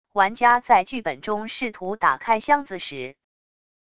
玩家在剧本中试图打开箱子时wav音频